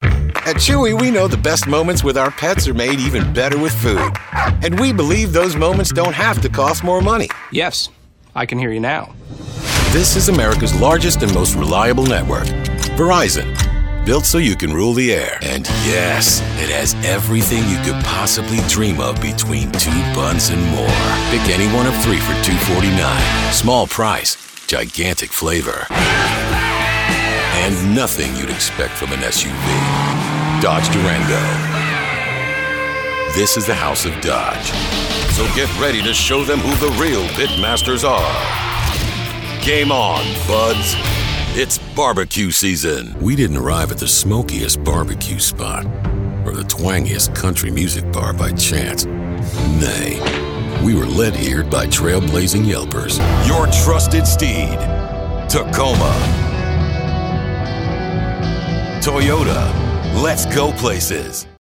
Authoritative, Razor Sharp, Witty and Conversational
Commercial Reel
Southern, NYC
Middle Aged